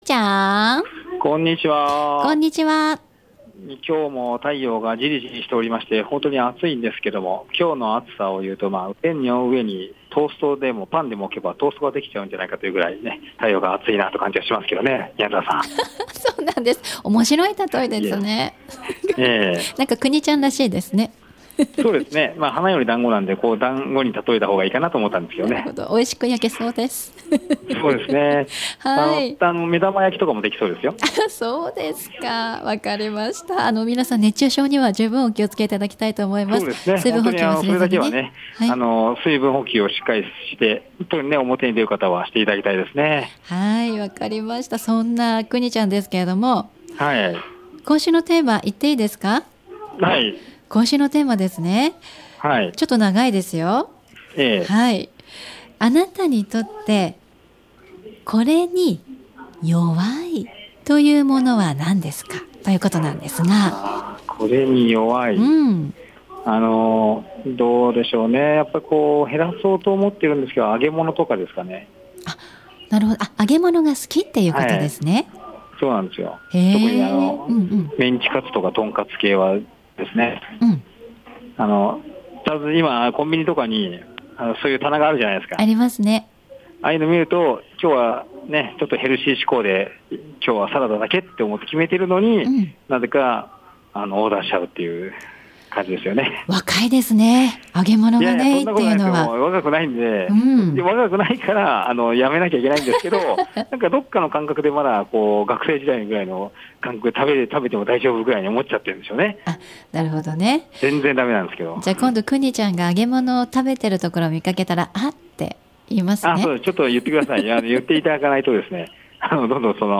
午後のカフェテラス 街角レポート
途中音声が途切れまして大変失礼いたしました。